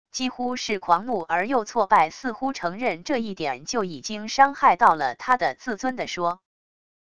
几乎是狂怒而又挫败――似乎承认这一点就已经伤害到了他的自尊――地说wav音频